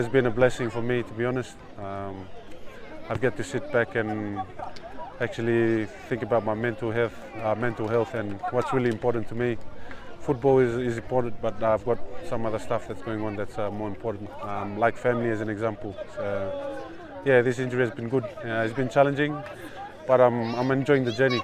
Speaking to local media at Albert Park in Suva, Kikau says the injury has also been a blessing for him as he had to spend more time with his family.